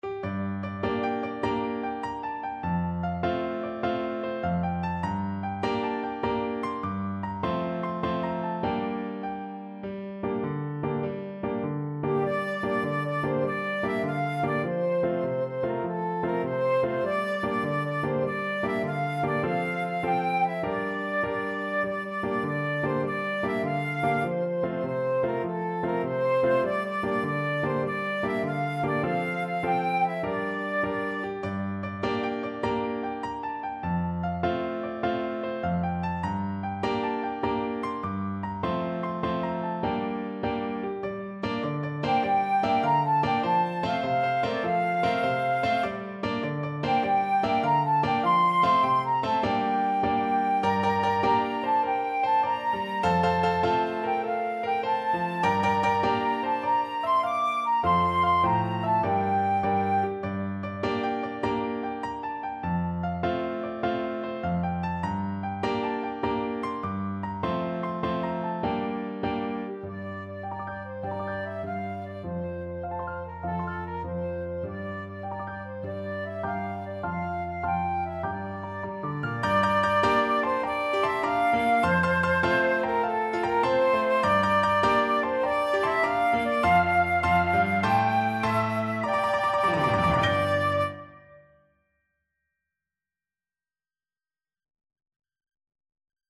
Allegro .=c.100 (View more music marked Allegro)
6/8 (View more 6/8 Music)